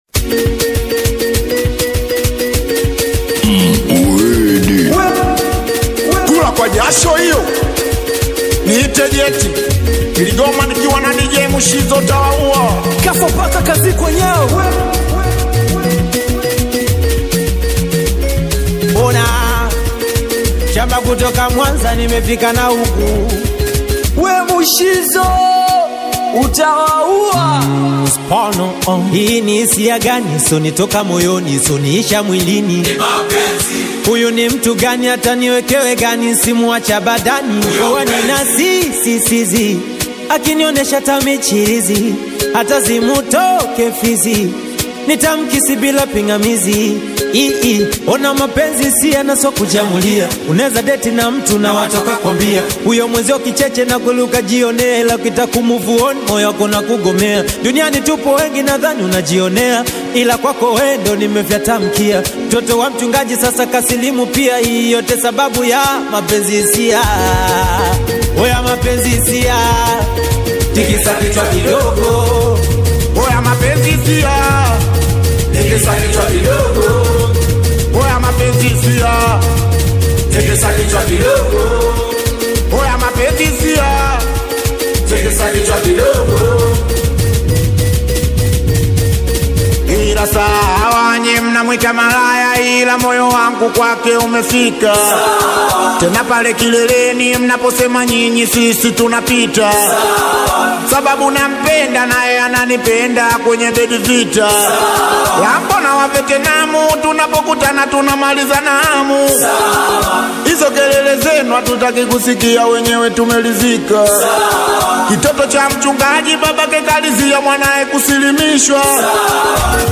SingeliAudio